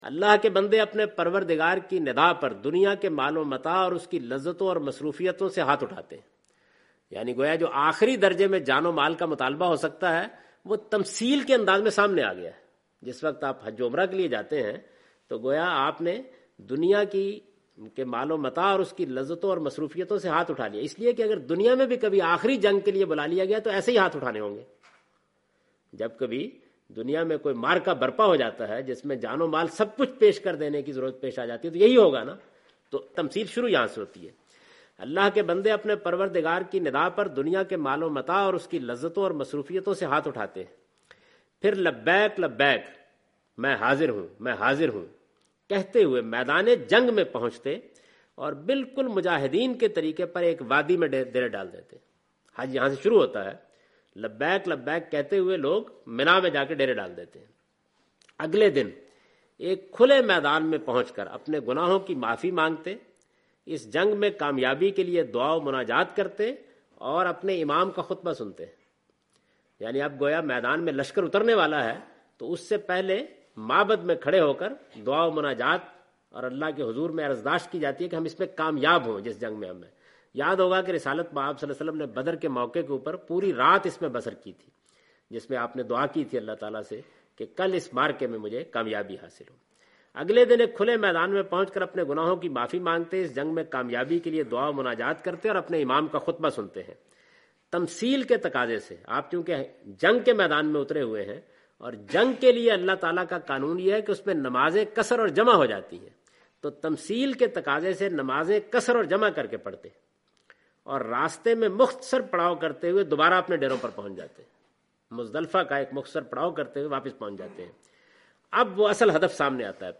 In this video of Hajj and Umrah, Javed Ahmed Ghamdi is talking about "Symbolic Expressions in Hajj".